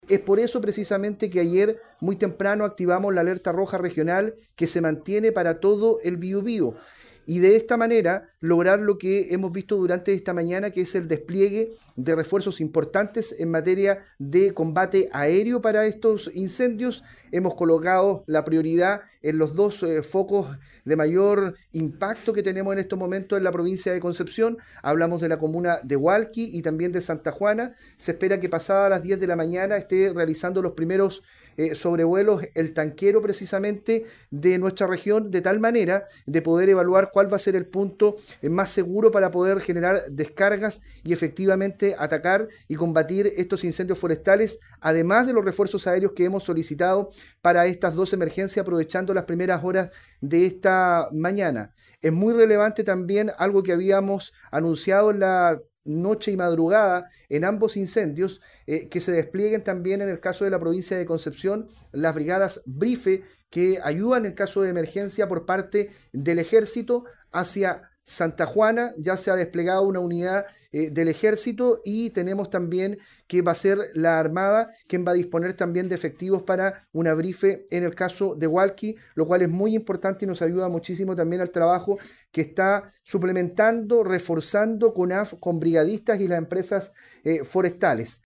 El delegado presidencial, Eduardo Pacheco informó que, de acuerdo con los antecedentes dados a conocer por Conaf, “durante la última jornada del domingo, tuvimos 22 focos de incendio en la Región del Biobío, probablemente la cifra más alta que hemos tenido durante la temporada”, añadió.